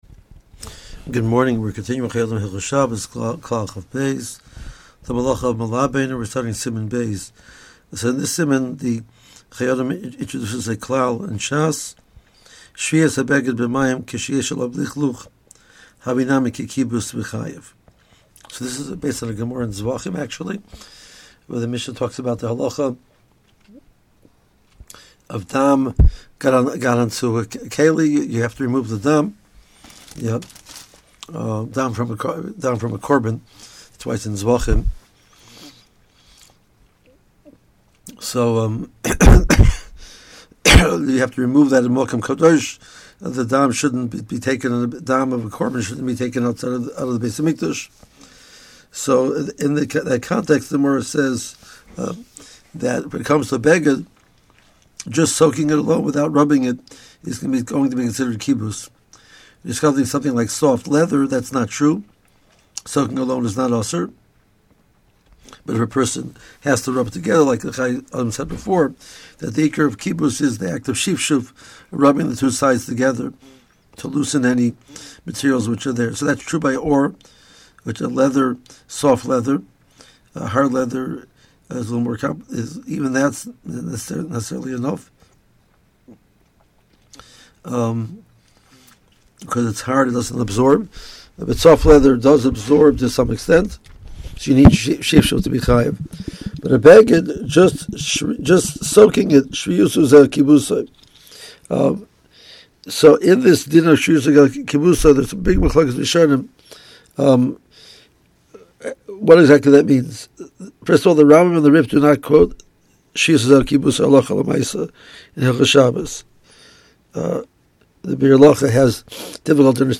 AUDIO SHIUR